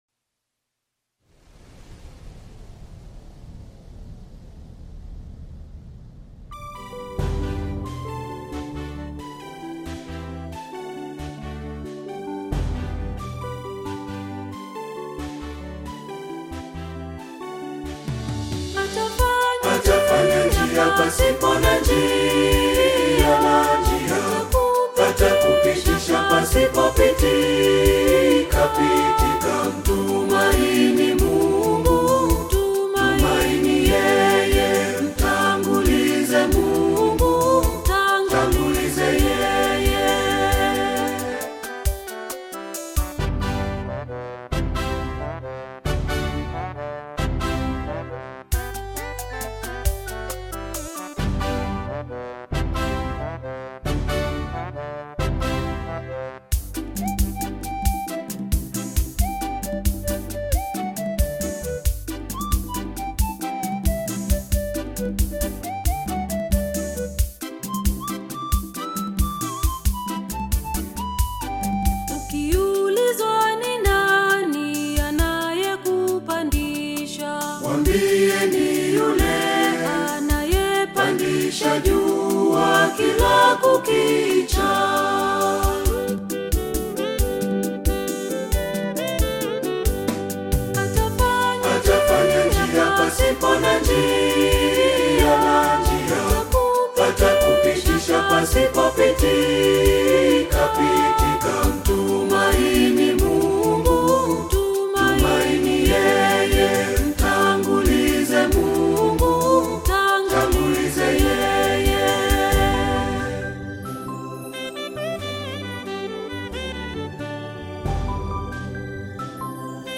Gospel music track
Tanzanian gospel artist, singer, and songwriter
Gospel song